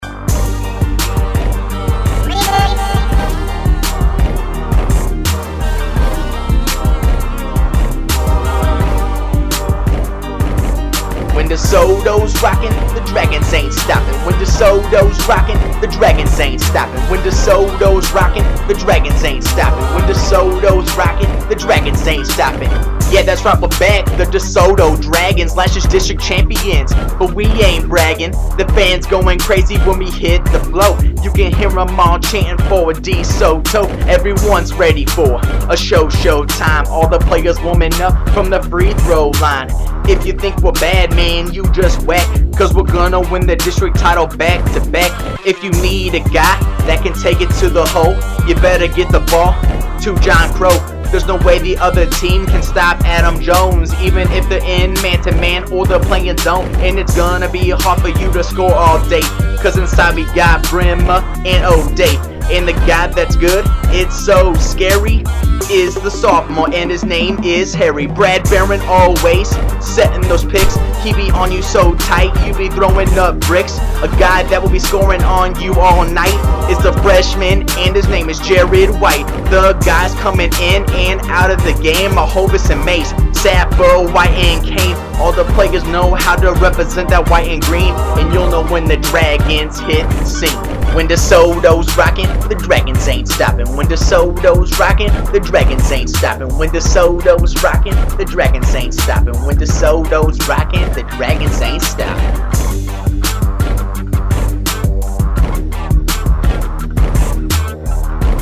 Desoto_Basketball_Rap_08_09.mp3